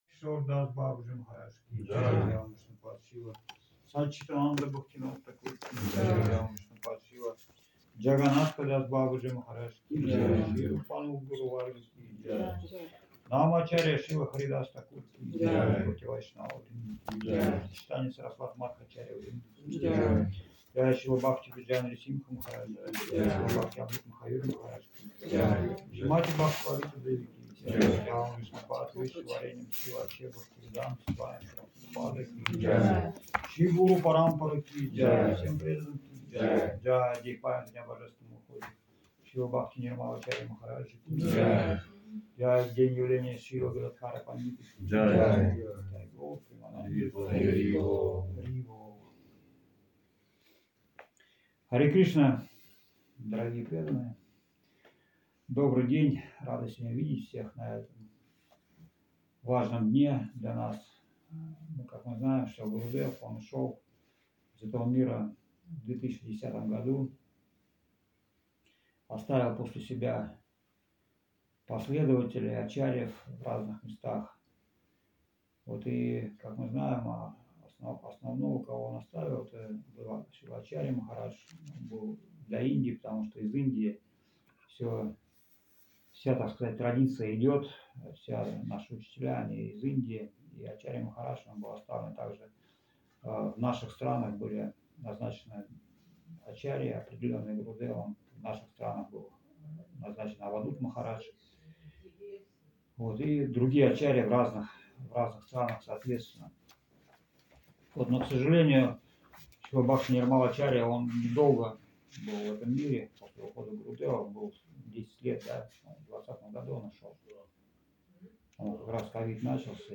Место: Сочи
Лекции полностью